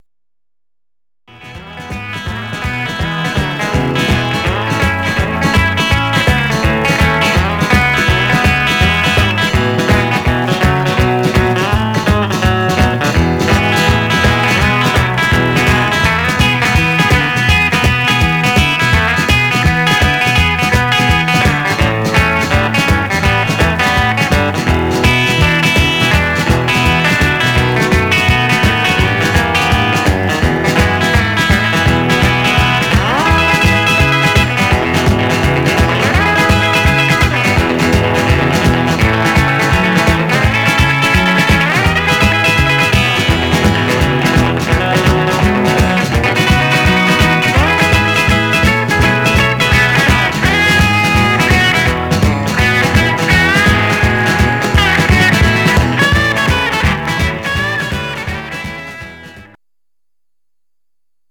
Mono
R & R Instrumental